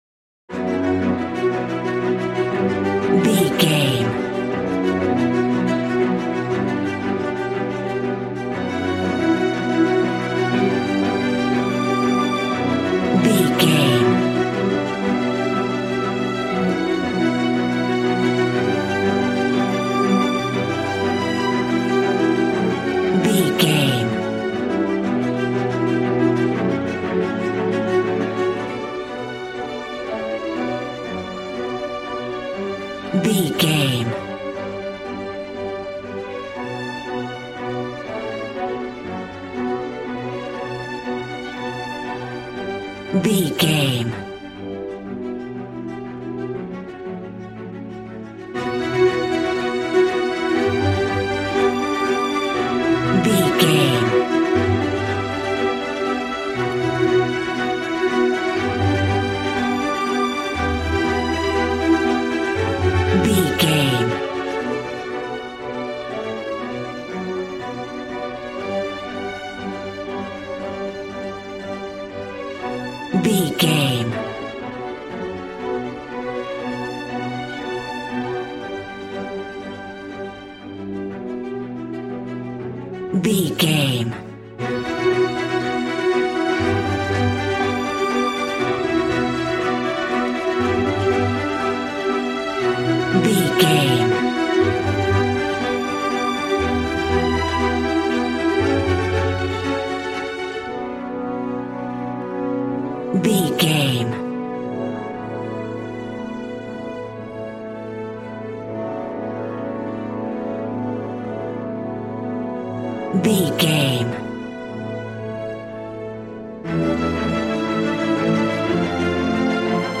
Regal and romantic, a classy piece of classical music.
Aeolian/Minor
regal
cello
violin
strings